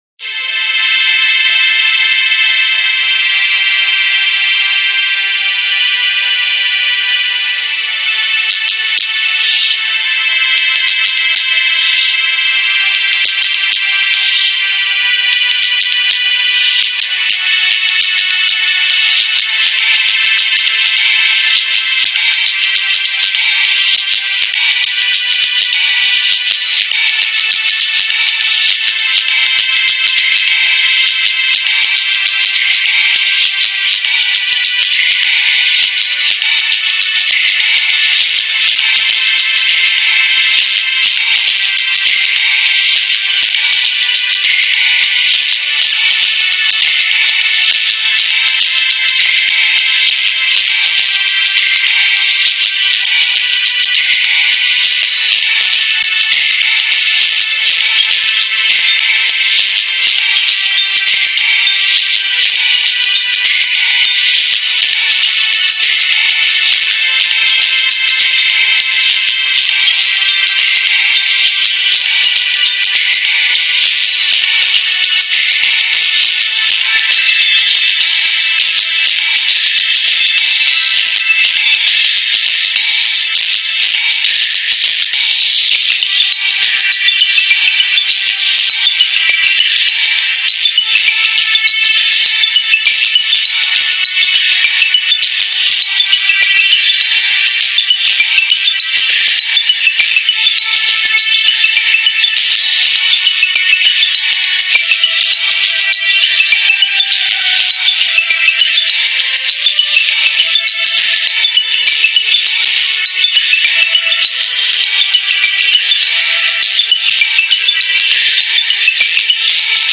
Old Radio Effect